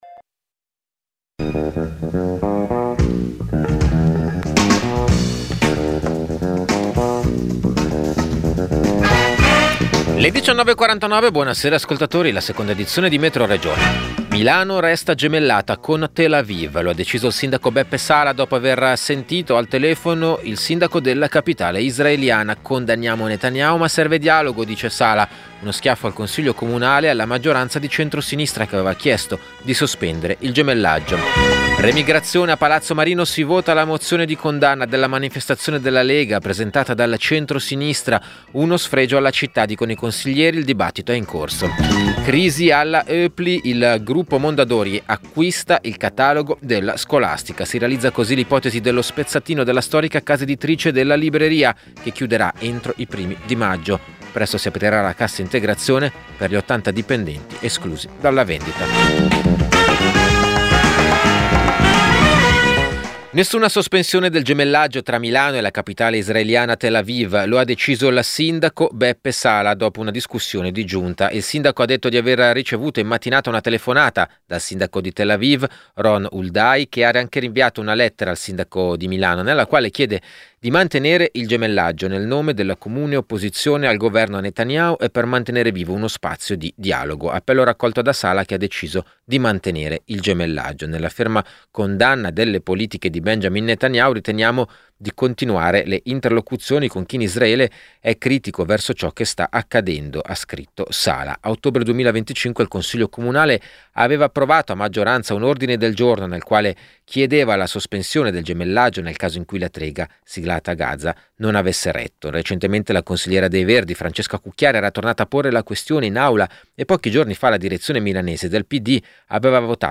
Metroregione è il notiziario regionale di Radio Popolare. Racconta le notizie che arrivano dal territorio della Lombardia, con particolare attenzione ai fatti che riguardano la politica locale, le lotte sindacali e le questioni che riguardano i nuovi cittadini.